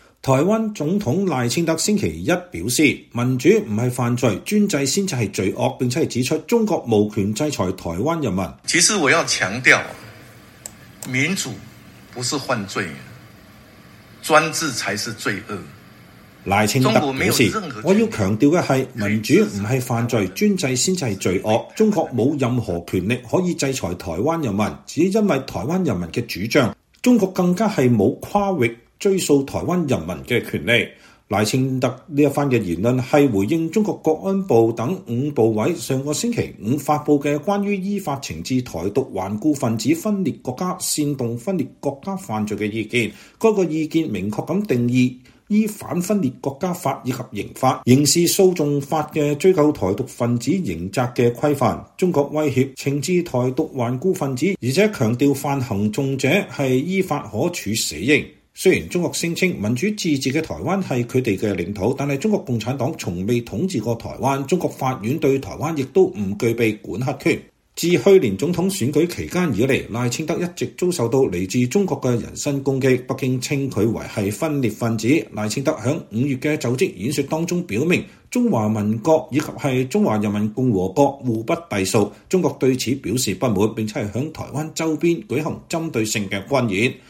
在台北總統府舉行的記者會上，賴清德先向中國南方最近暴雨成災的災民表達慰問，期望中國災後重建順利。